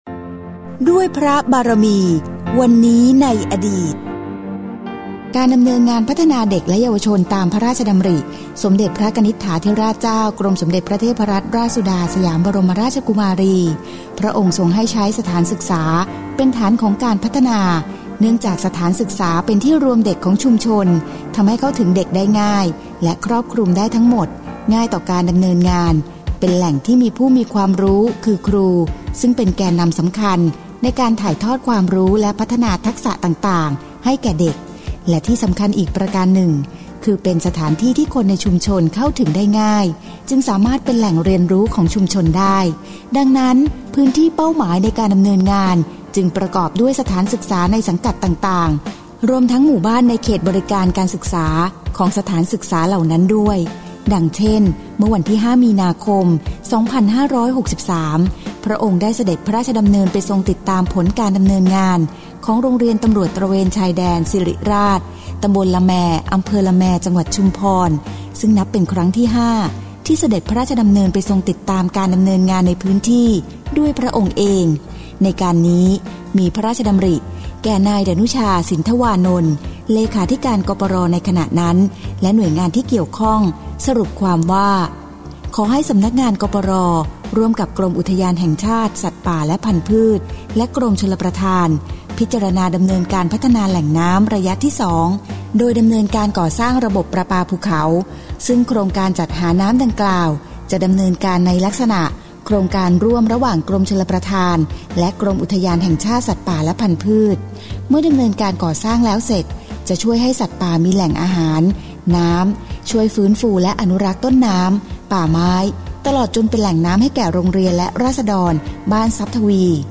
สารคดี